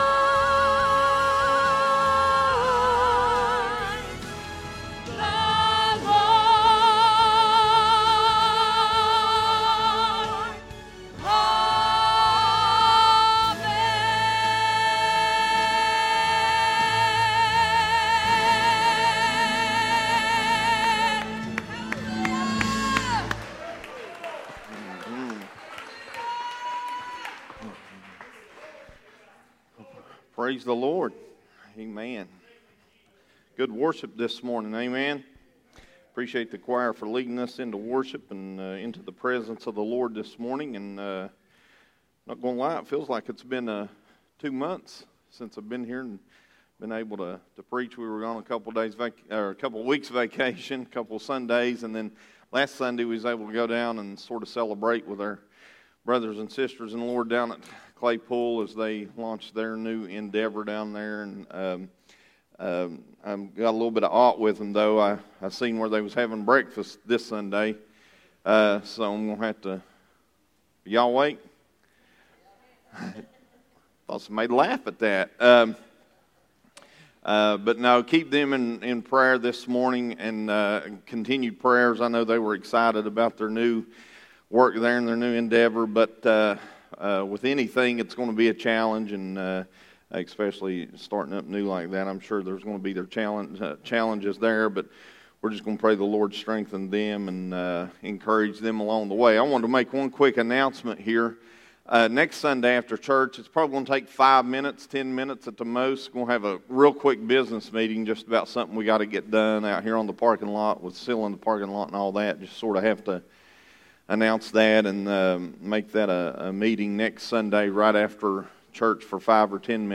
Red Rover...Red Rover Sermon Series